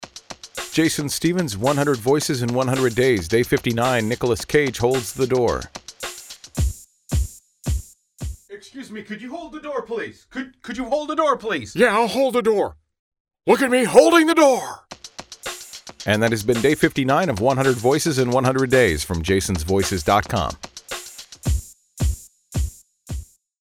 For today’s episode, I present my Nicolas Cage impression.  The premise:  Mr. Cage has just entered a room.
Tags: celebrity voices, hold the door, Nicolas Cage impression